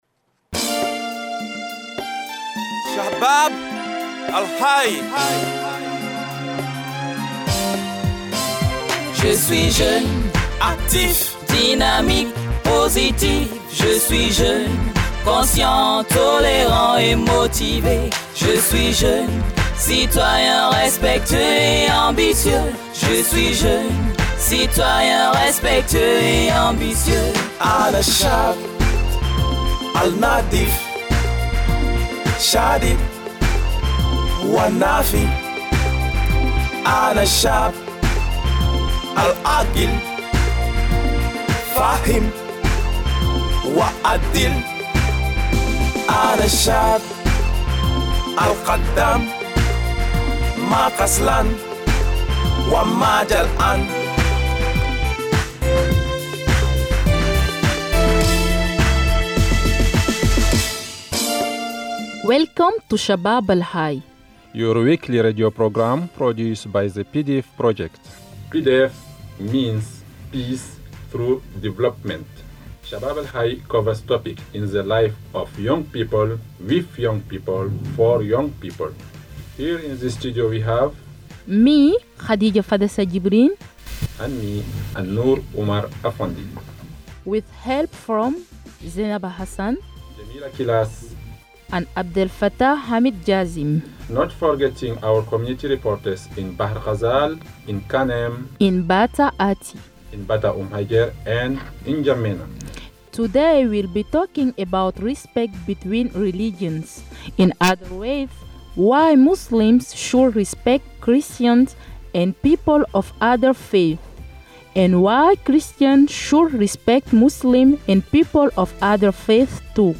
Listen to Gwadaben Matasa (Youth Boulevard) radio show from Niger, recorded and translated into English by our radio team in the country Listen to one of the Chabab Al Haye (Youth Alive) radio episode from Chad, translated in English